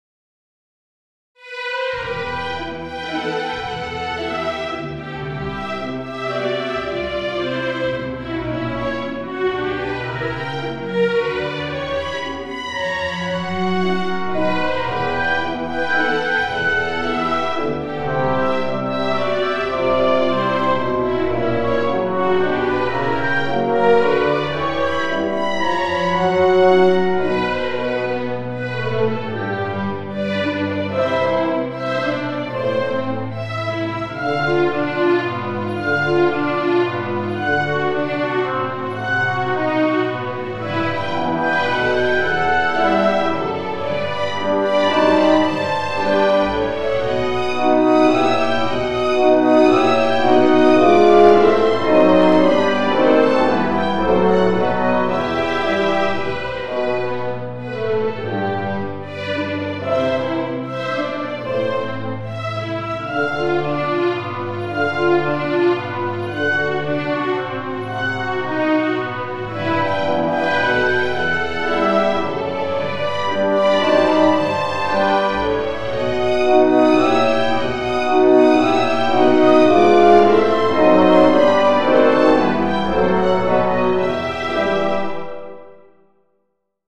per orchestra da camera